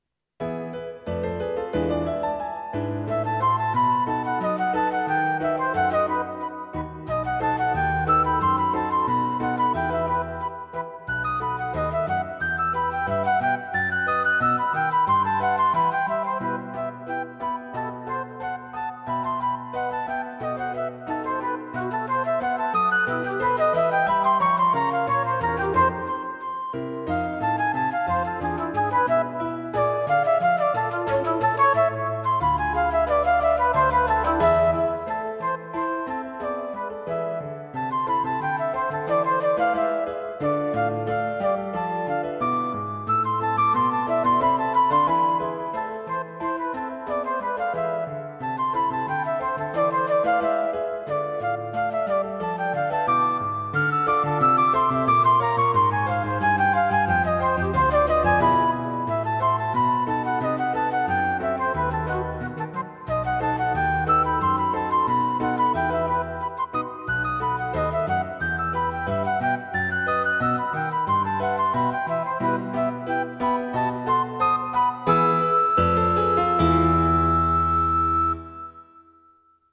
Flute Solos For Flutes and Piano